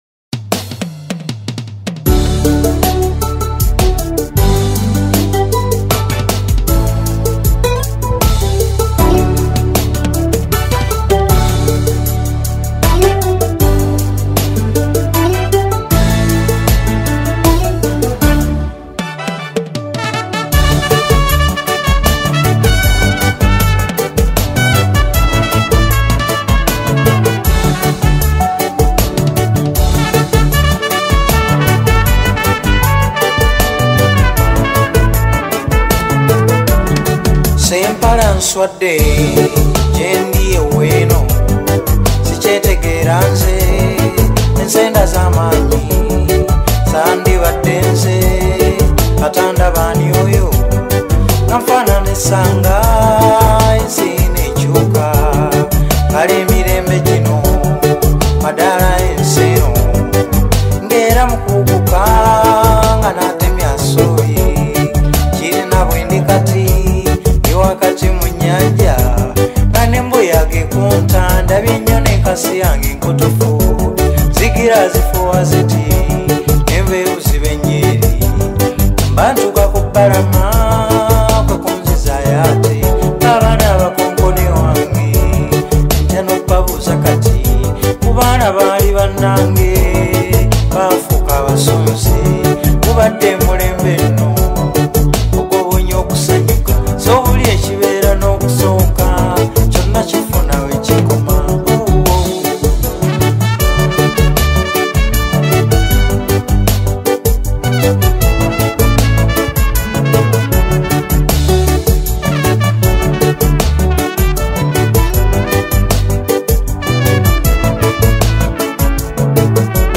slow, firm, and confidently delivered.